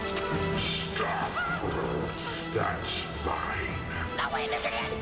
I apologize for the low sound quality of some of the dialog bites this time around.